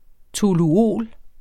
Udtale [ toluˈoˀl ]